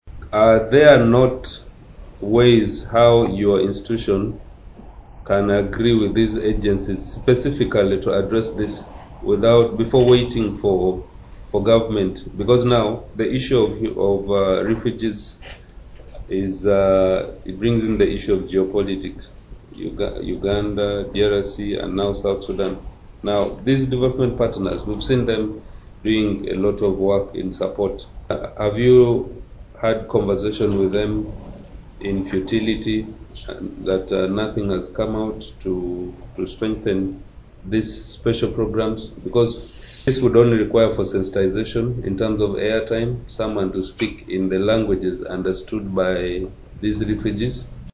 In response to the UHRC's appeal, Bernard Odoi One, the youth member of parliament for the Eastern region, offered valuable advice. He encouraged the UHRC to seek support from other partners, recognizing that collaborative efforts could help mitigate the challenges posed by insufficient funding.